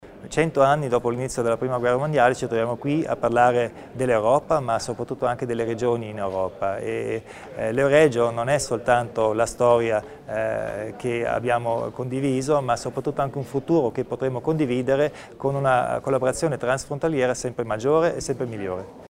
Un momento di confronto per capire dove vanno l'Alto Adige e l'Euregio, quale sarà il futuro delle Regioni nell'UE, quanto sia matura una governance di multilivello: sono alcuni dei temi accennati oggi (4 luglio) dal presidente della Provincia Arno Kompatscher in apertura del convegno sul rapporto tra Europa e Regioni.